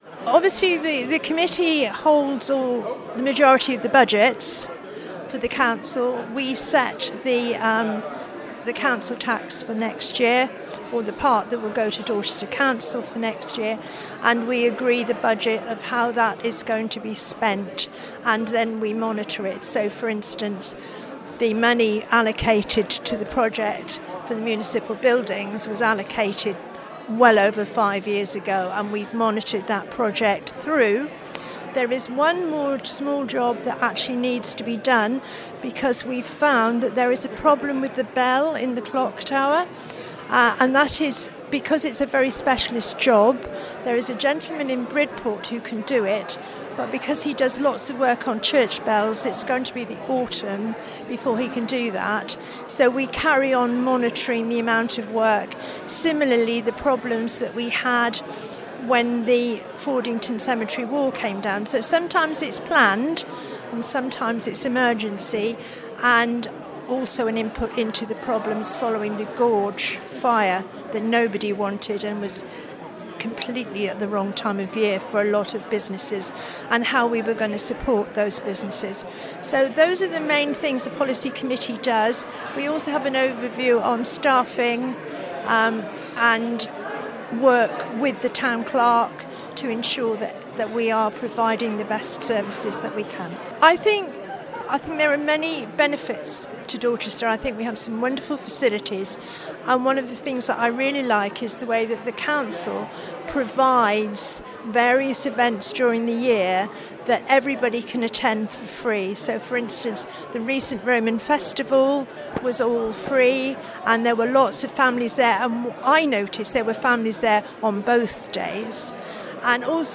Reporting from Dorchester’s Informal Town Meeting